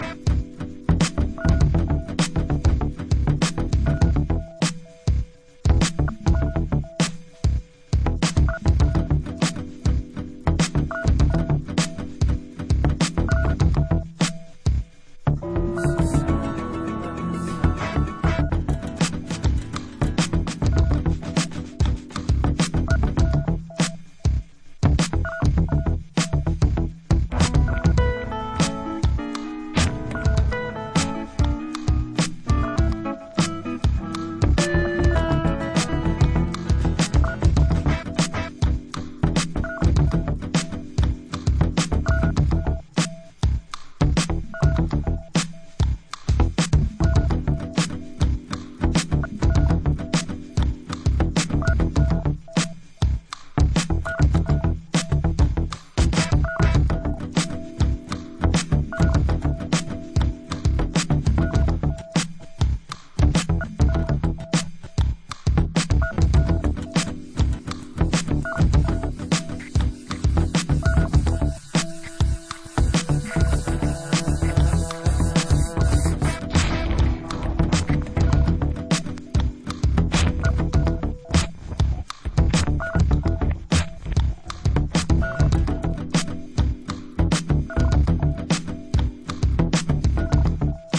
dreamy, electro acoustic perambulation
Electronix